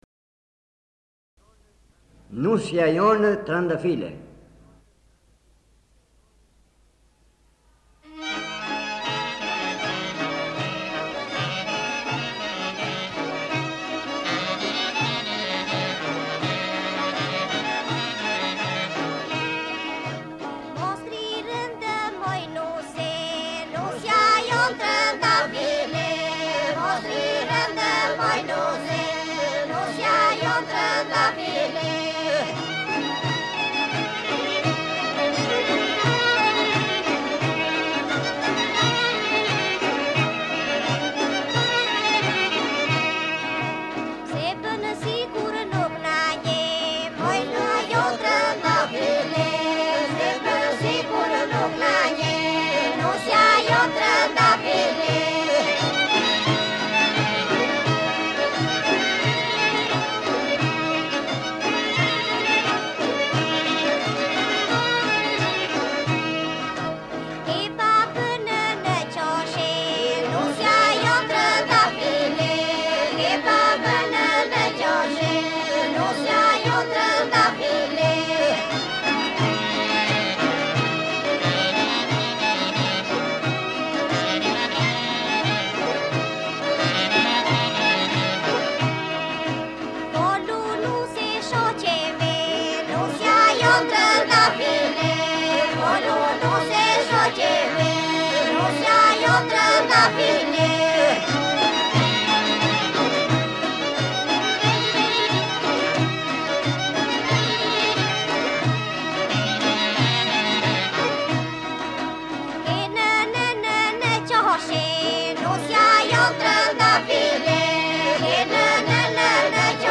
Grup popullor iso polifonik me vegla i viteve 1960-1970.
Këto saze përcollën me saze këngën tradicionale iso-polifonike labe të zonës së Labovës.